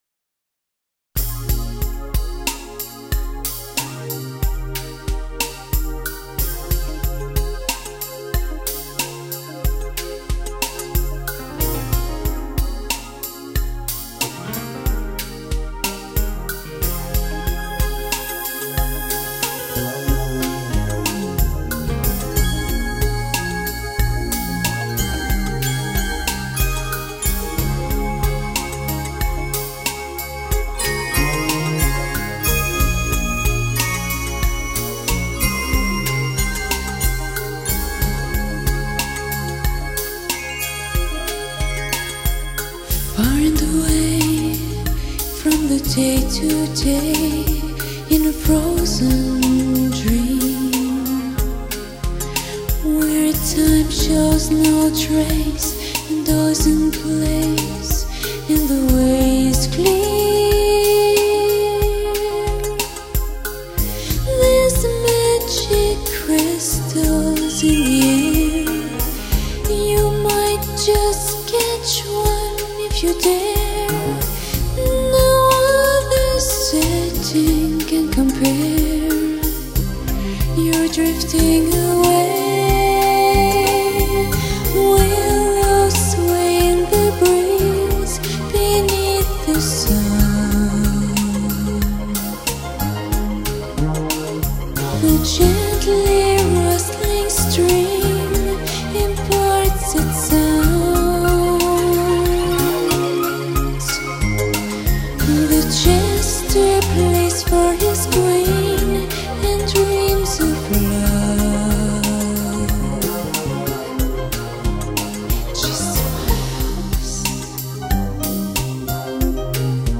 这里的“鲜”，是指人声和乐器音色的鲜活和鲜艳程度，十分能博得大多数烧友的青睐。